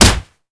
kick_heavy_impact_01.wav